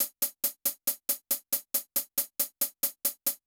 BRUSH     -L.wav